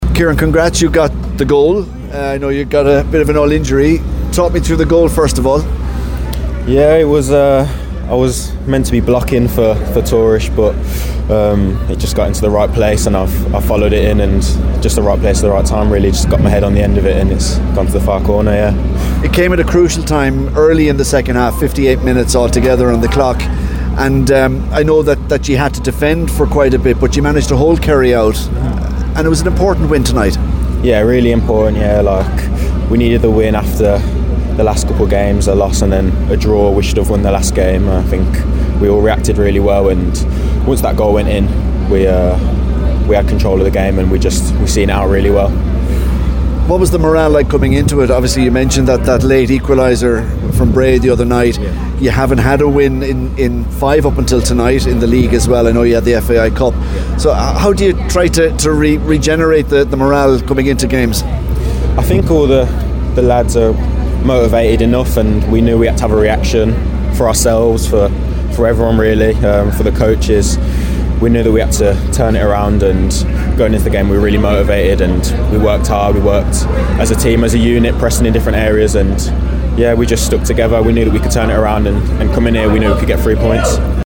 After the game, goal-scorer